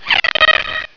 Airhorn
airhorn.wav